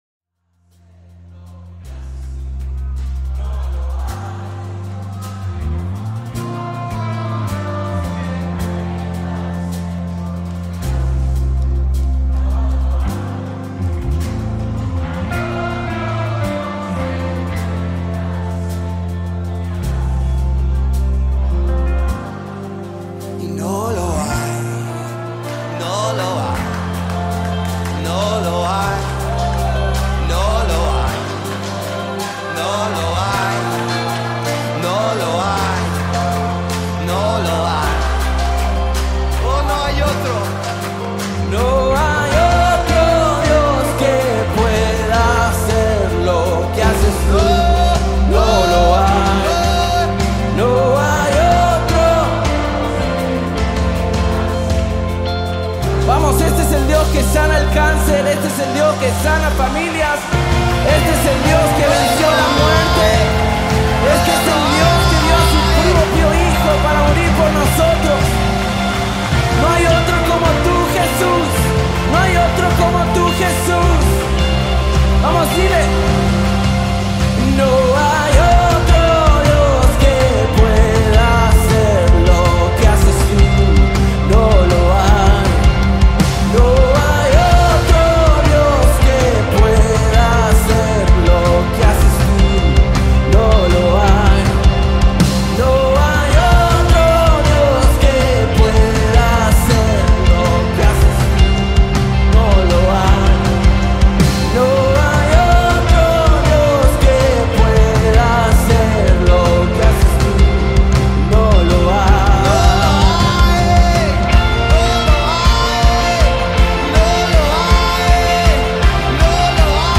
Foreign Artists - Gospel Songs Collection